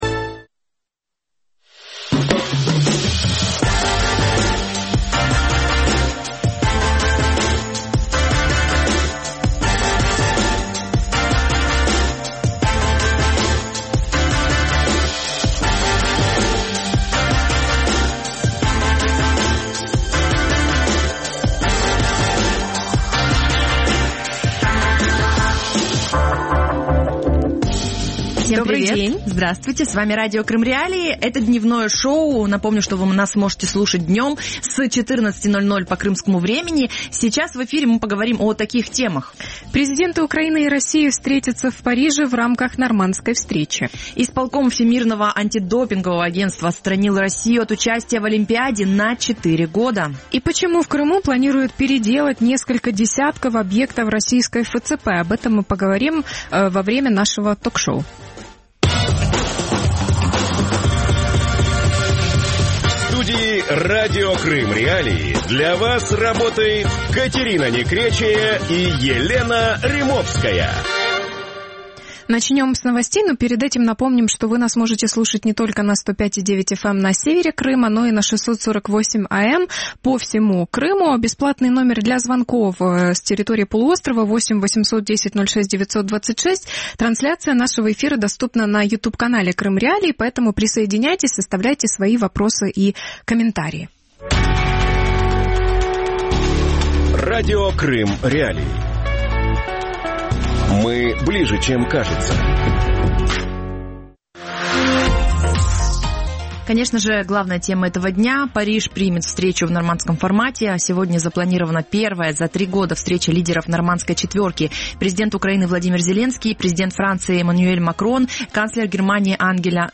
Куда исчезли российские деньги в Крыму? | Дневное ток-шоу